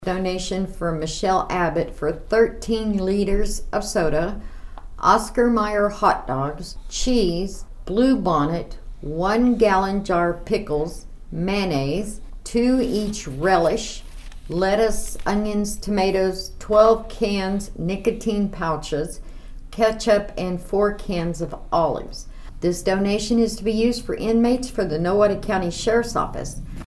Spurgeon explains.